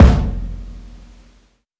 mass_live_kick.wav